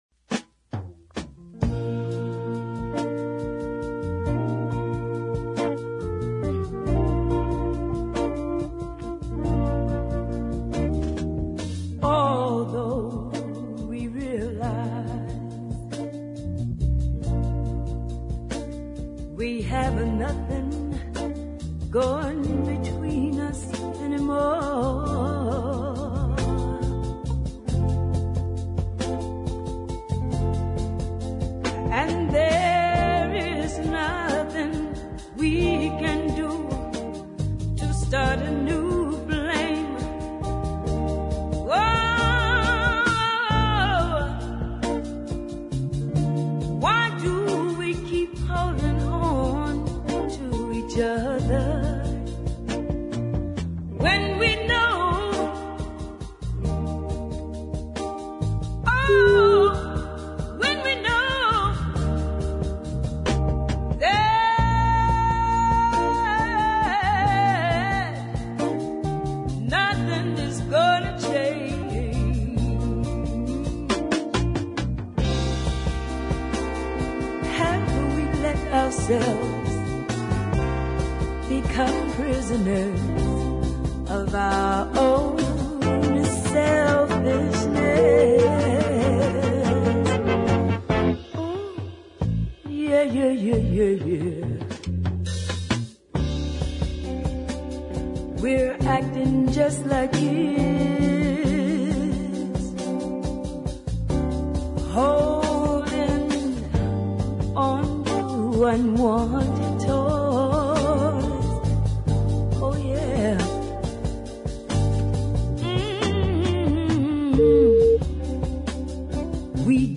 heavy duty ballad
gospel phrasing